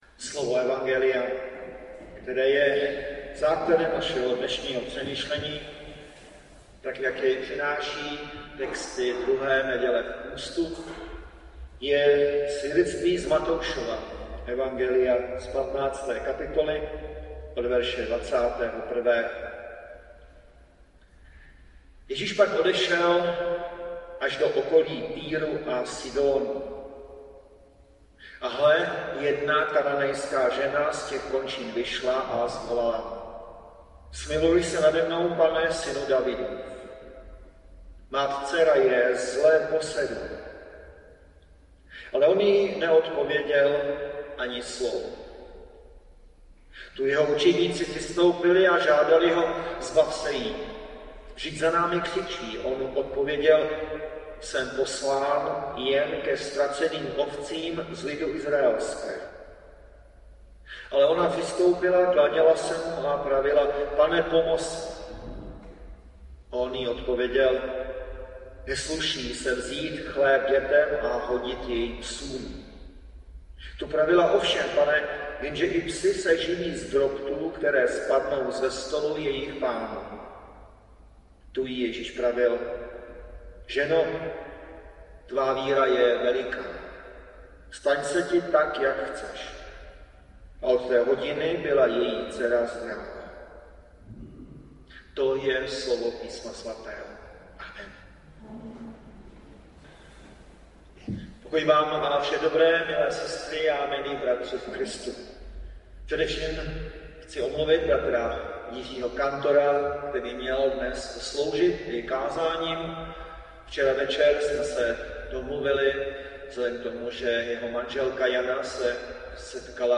Příspěvek byl publikován v rubrice Kázání .